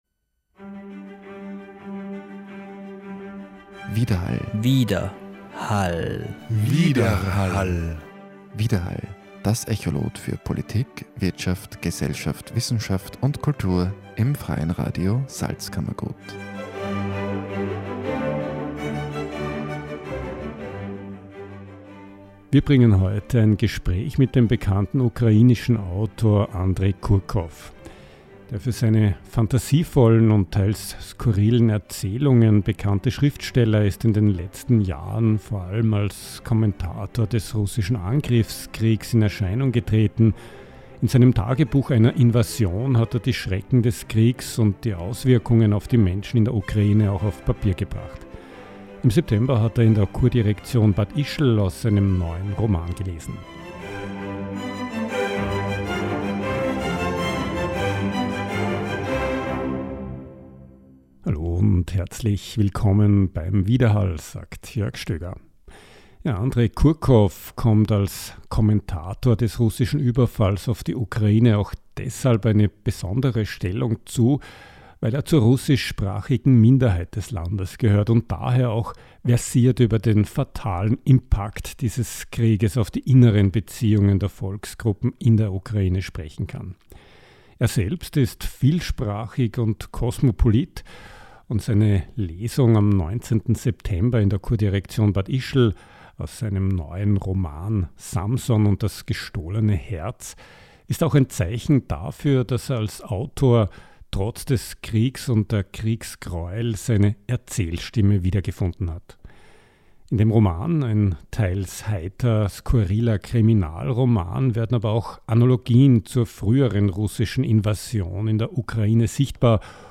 Gespräch mit dem ukrainischen Autor Andrej Kurkow, der in seinem „Tagebuch einer Invasion“ von den Auswirkungen des russischen Angriffskriegs erzählt.
Am nächsten Tag gab der in St. Petersburg geborene Schriftsteller dem FRS ein Interview. Darin spricht er darüber, warum er sich nach Jahren, in denen er sich ausschließlich mit den Folgen des Krieges beschäftigt hat nun wieder dem Schreiben von Romanen widmen kann. Er schildert auch die Situation in der Ukraine und die gesellschaftlichen Veränderungen und geht auch auf die Problematik der russisch-sprachigen Minderheit ein.